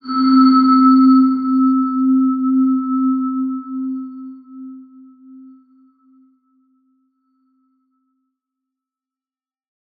X_BasicBells-C2-mf.wav